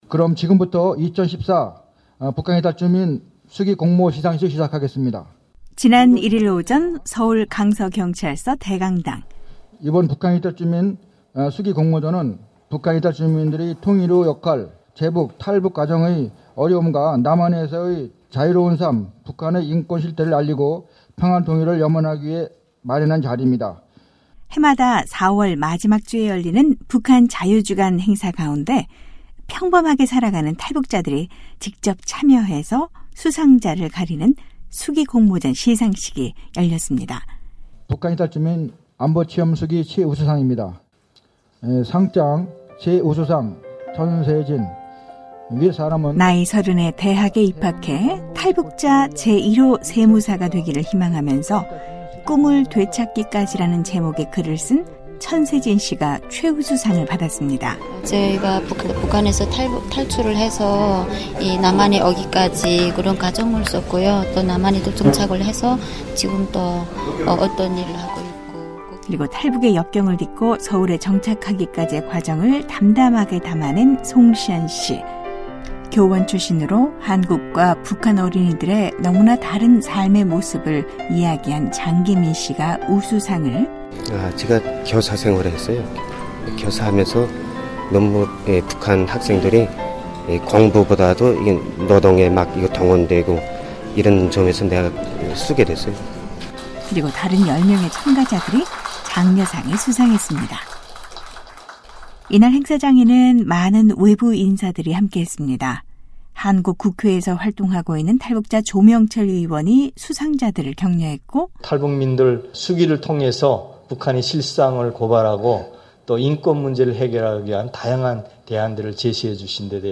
오늘은 서울에 사는 탈북자들이 주인공인 한 시상식 현장으로 안내하겠습니다. 지난주 북한자유주간 행사의 하나로, 탈북자들의 정착수기를 받아 그 수상자를 가리는 탈북자수기공모전 시상식이 있었는데요.